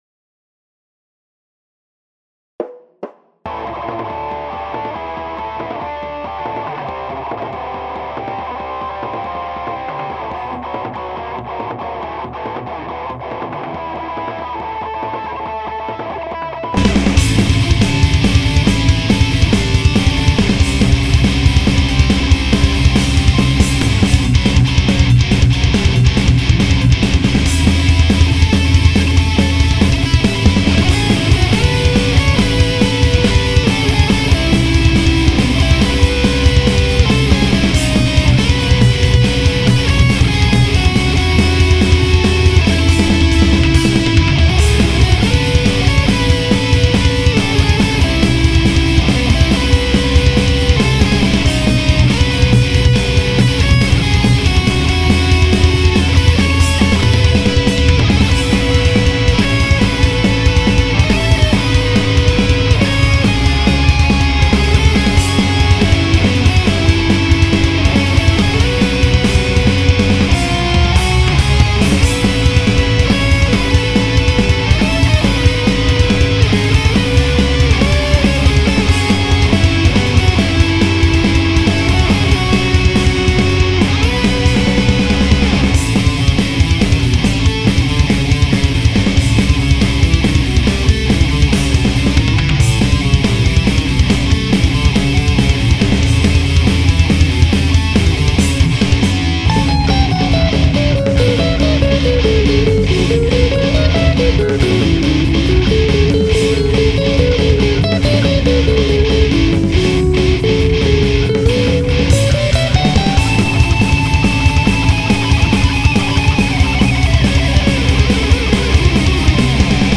（苦笑 パンクなハードロック？兎角、原曲の冬の街並み感は皆無です。 初夏のライブフェス感を感じていただければと思います。
＊：最初音量は小さいです、注意してください。